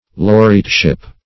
\Lau"re*ate*ship\